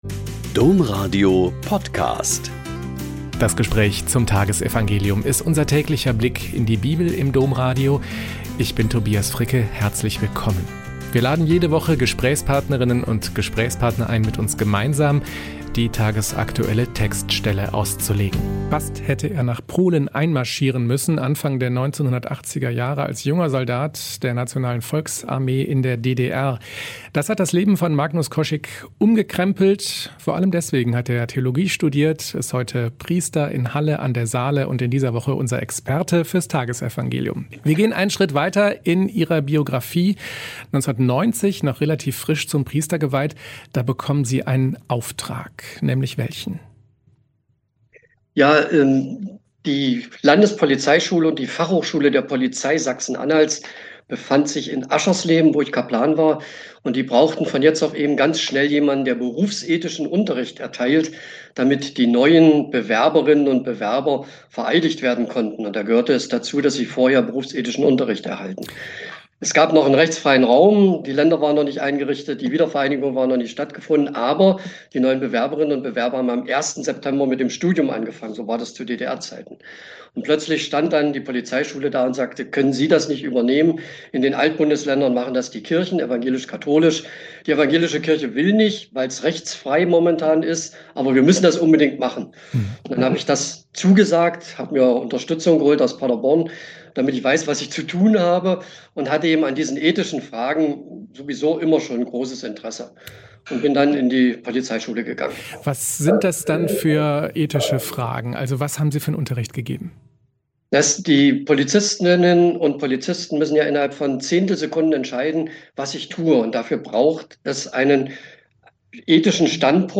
Mk 7,14-23 - Gespräch